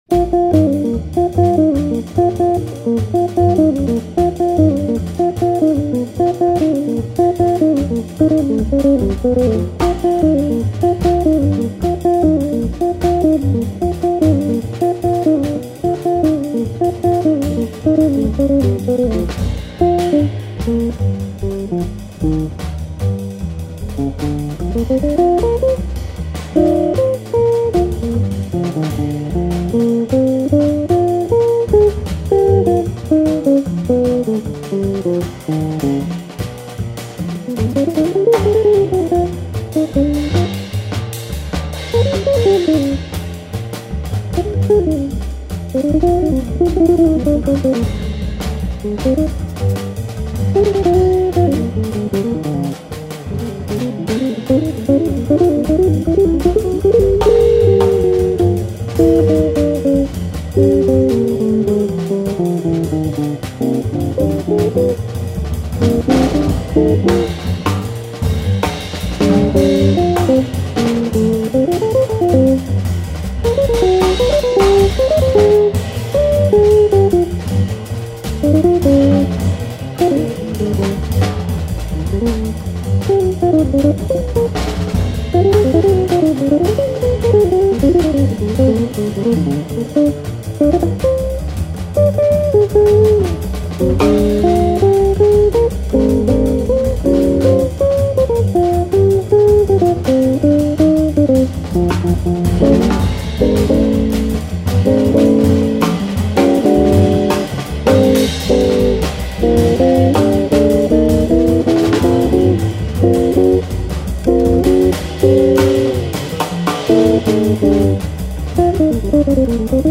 contrebasse
batterie